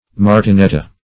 Search Result for " martineta" : The Collaborative International Dictionary of English v.0.48: Martineta \Mar`ti*ne"ta\, n. [Cf. Sp. martinete.]